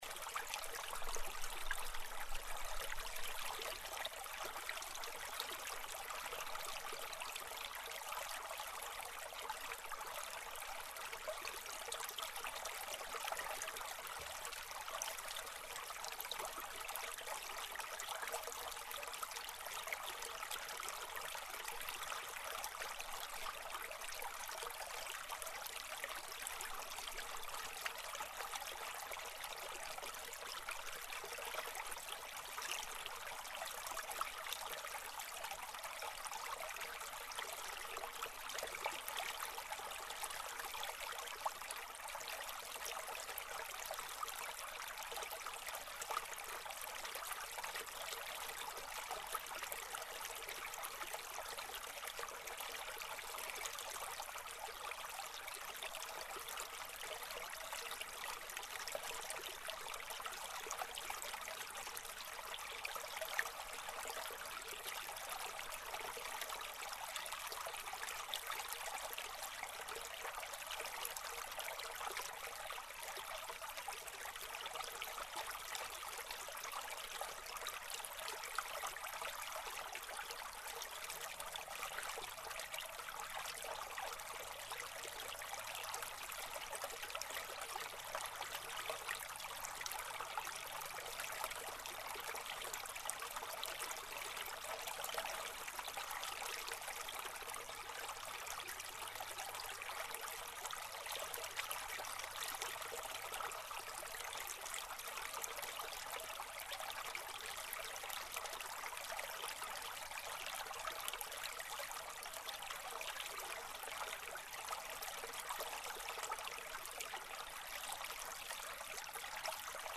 Звуки ручья